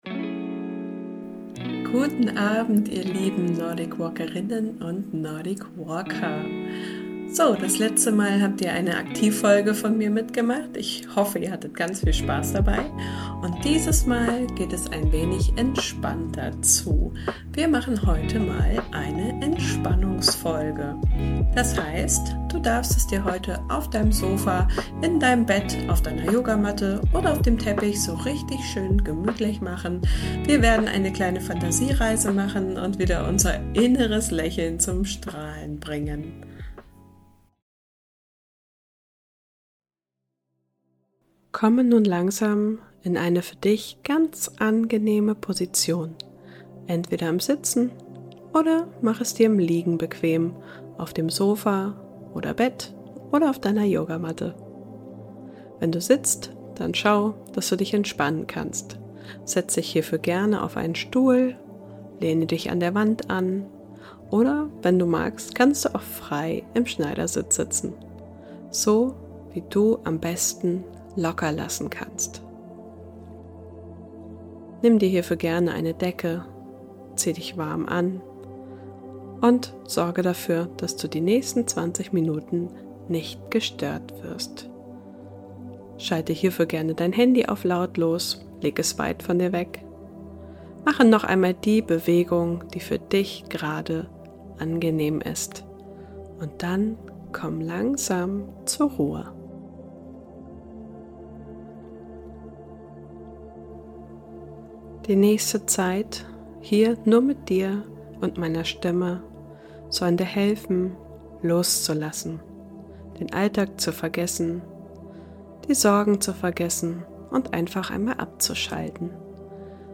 Dann suche dir für die nächsten 20 Minuten ein ruhiges Plätzchen, sorge dafür dass du völlig ungestört bist und folge meiner Stimme durch diese kleine Fantasiereise durch deinen Körper.
Meditation_Wintervibes(1).mp3